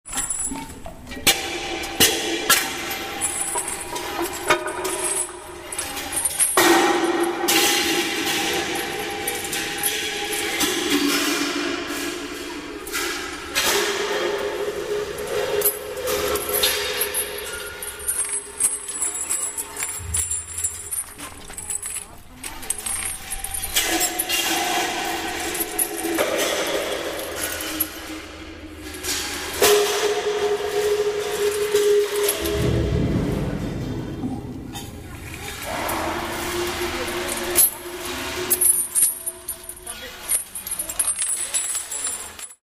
Звуки колодца
Звук глибокого колодязя з ефектом луни